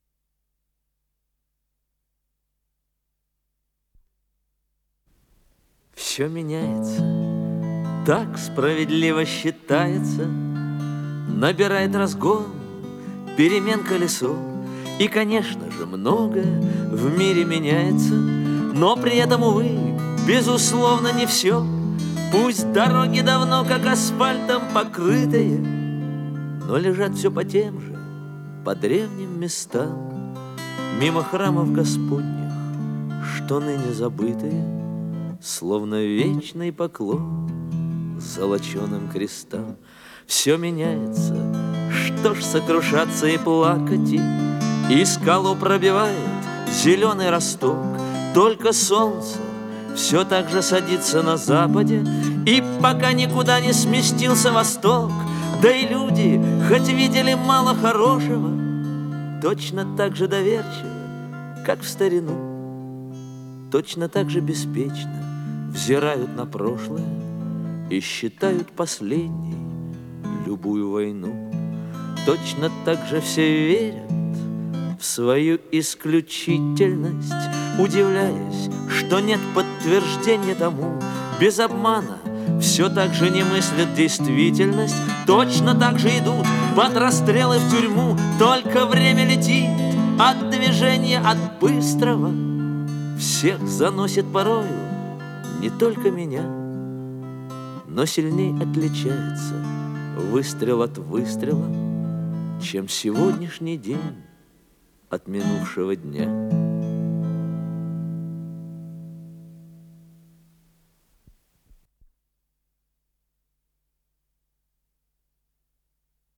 с профессиональной магнитной ленты
пение под гитару
Скорость ленты38 см/с